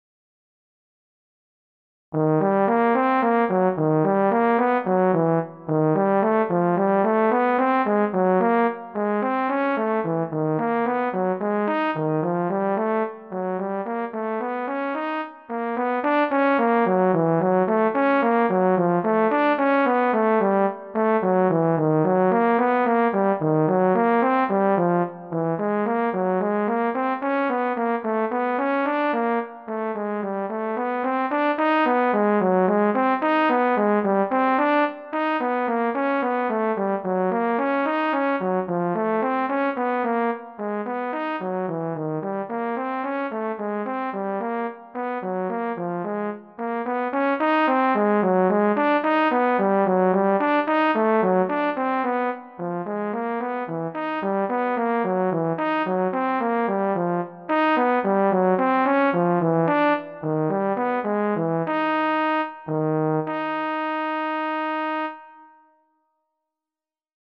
(Prélude)